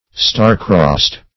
Search Result for " star-crossed" : The Collaborative International Dictionary of English v.0.48: Star-crossed \Star"-crossed`\ (st[aum]r"kr[o^]st`; 115), a. Not favored by the stars; ill-fated.